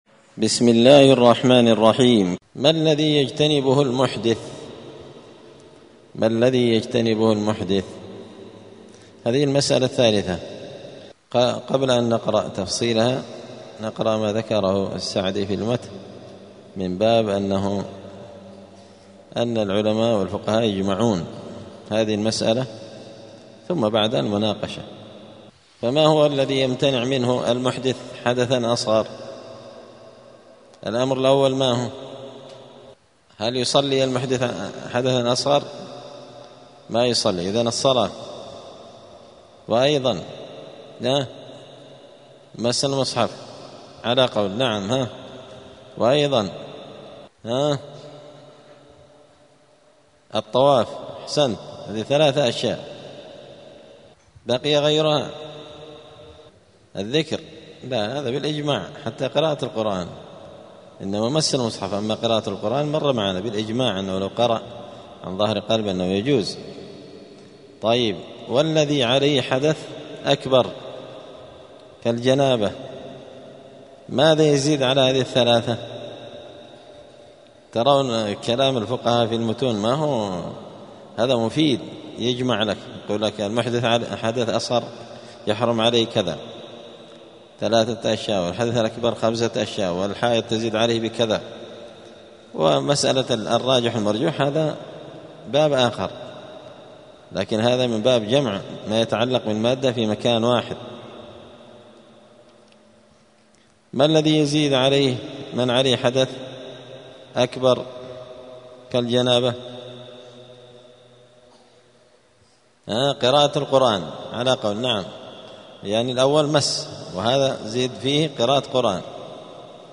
دار الحديث السلفية بمسجد الفرقان قشن المهرة اليمن
*الدرس الستون [60] {باب ما ينقض الوضوء حكم الطواف بغير وضوء}*